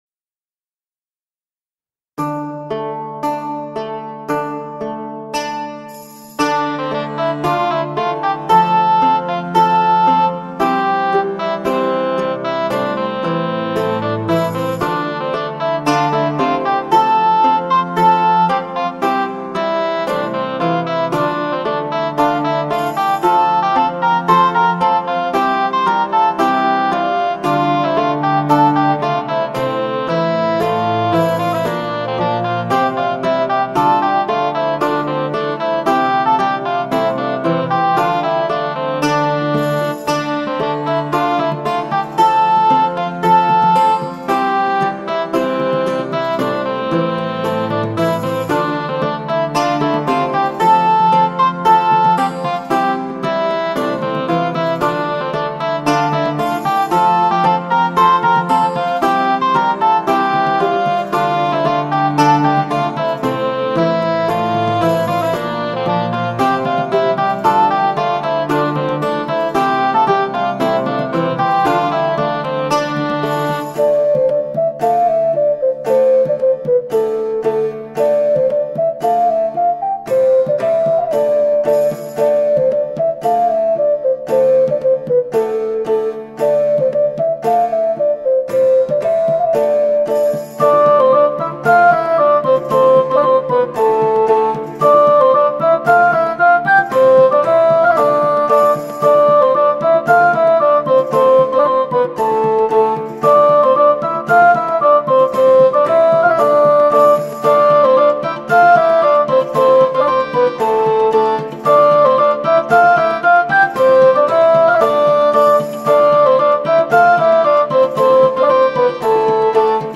medieval_musique1.mp3